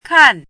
chinese-voice - 汉字语音库
kan4.mp3